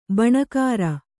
♪ baṇakara